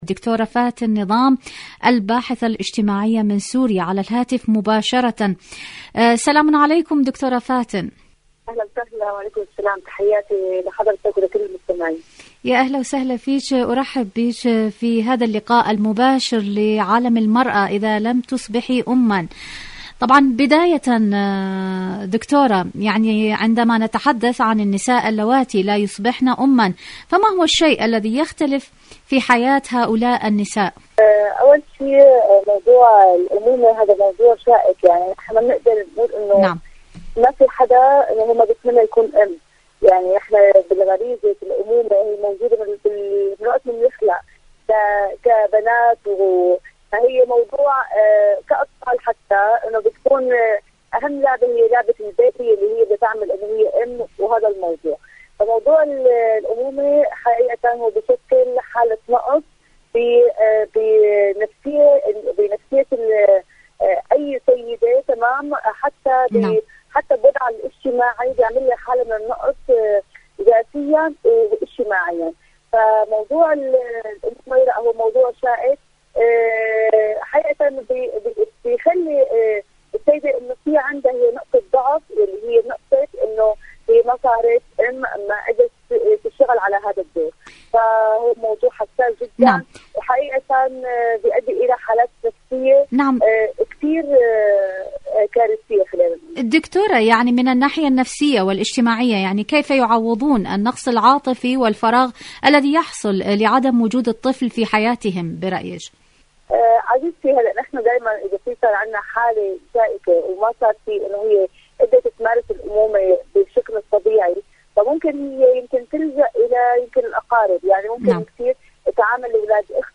مقابلات برامج إذاعة طهران العربية برنامج عالم المرأة المرأة الأم إذا لم تصبحي أما مقابلات إذاعية شاركوا هذا الخبر مع أصدقائكم ذات صلة دور العلاج الطبيعي بعد العمليات الجراحية..